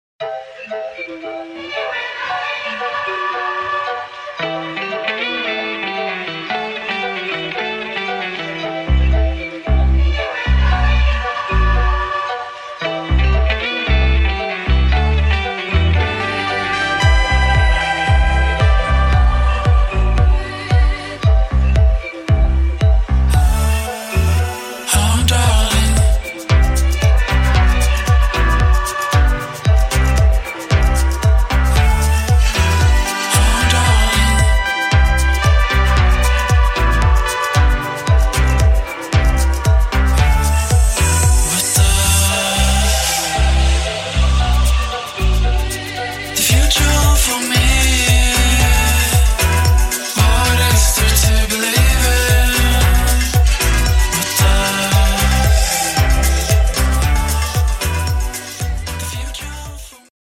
[ POP / ROCK / INDIE ]